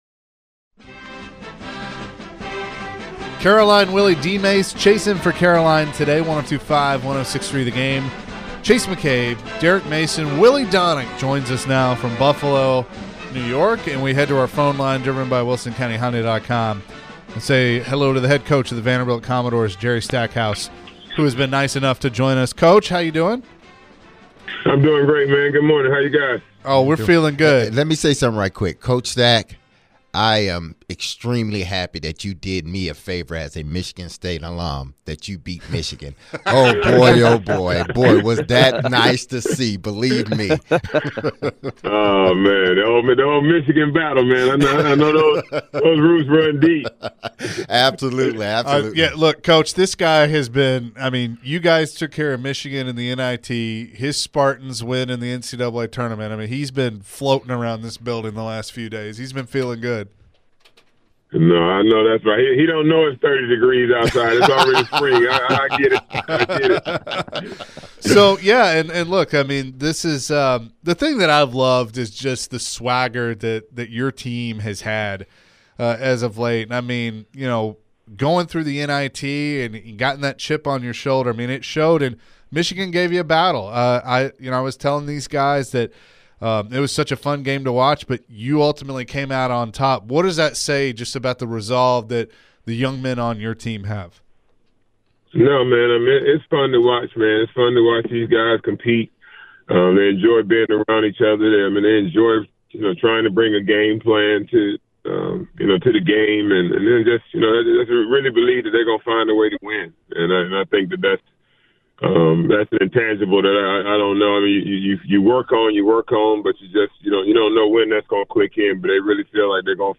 Vandy men's basketball Head Coach Jerry Stackhouse joined the show prior to their next matchup in the NIT against UAB tomorrow.